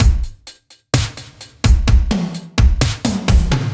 Index of /musicradar/french-house-chillout-samples/128bpm/Beats